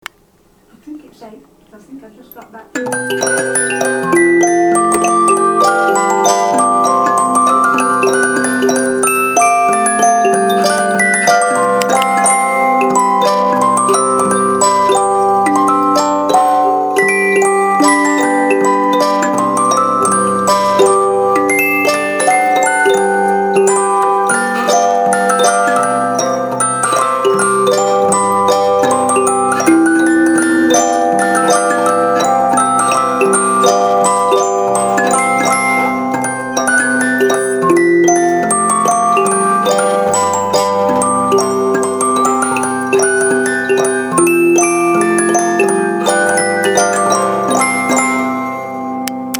Unknown Tunes on Ducommun Giraud Cylinder, Part 2